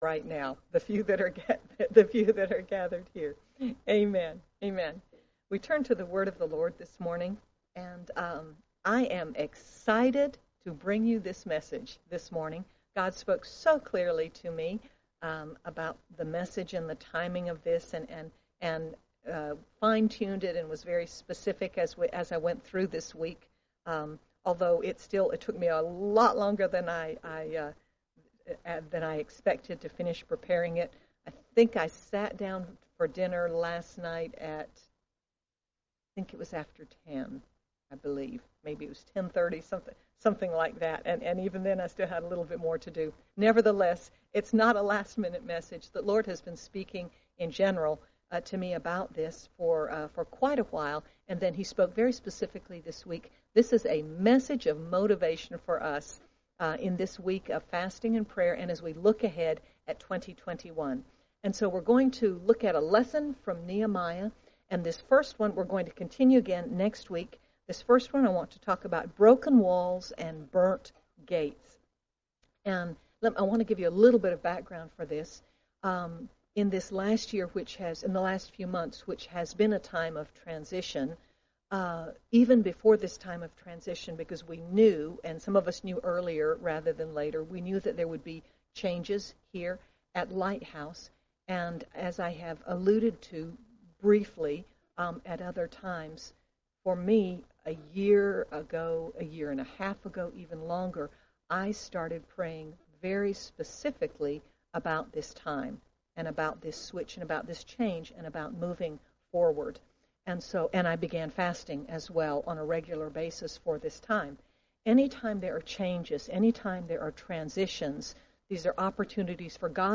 Learn from Nehemiah how to hear from God, live out His plan, and be used by God in the face of overwhelming odds and opposition. Sermon by